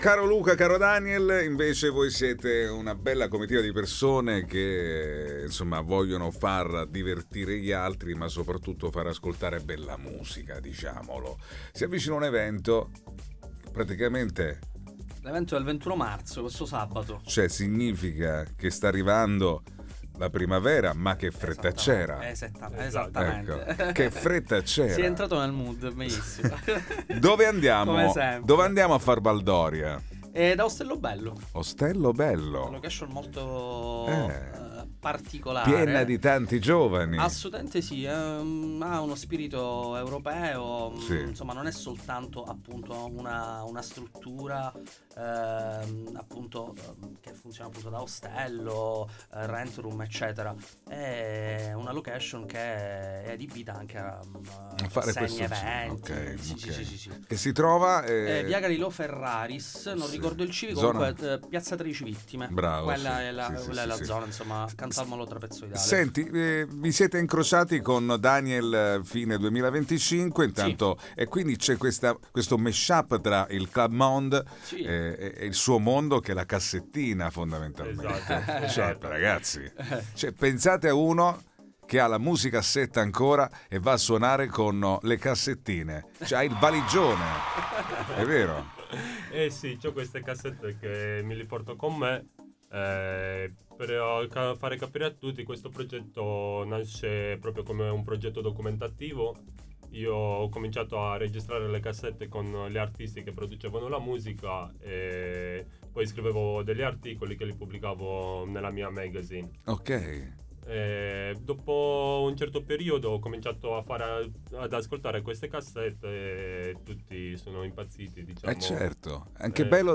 INTERVISTA
Interviste